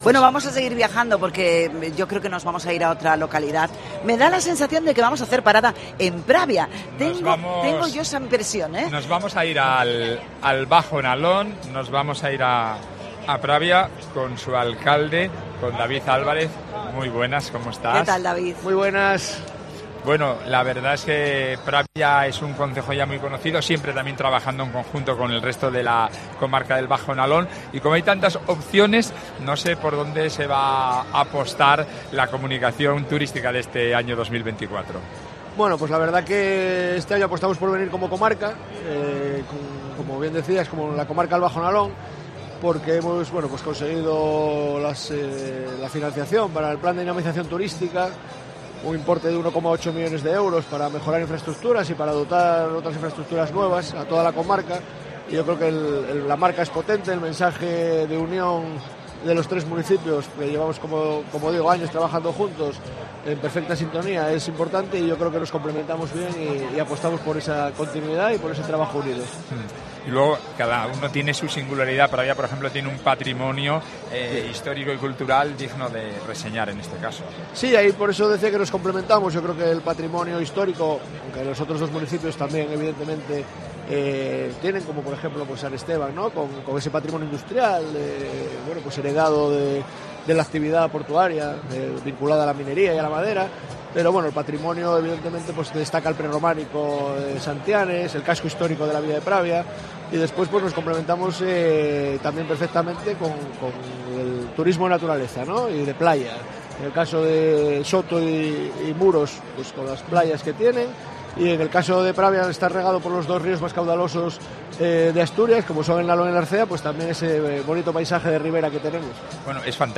FITUR 2024: Entrevista a David Álvarez, alcalde de Pravia